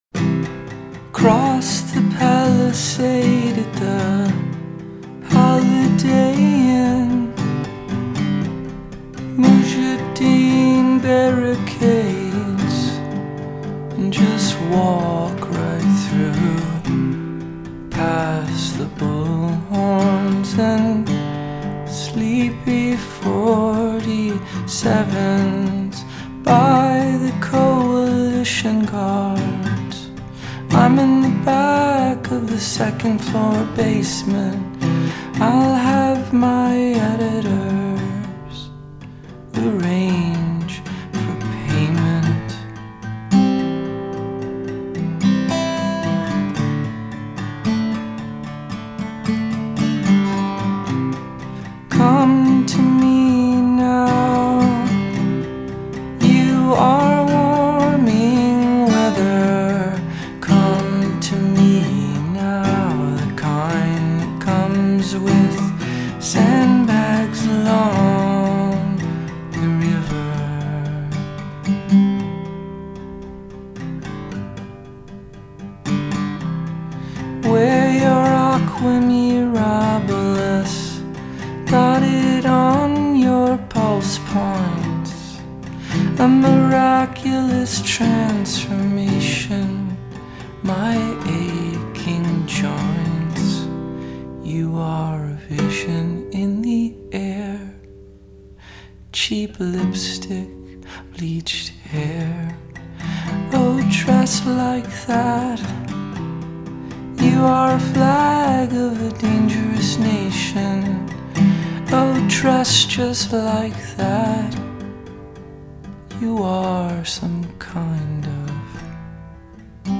Genre: indie rock / pop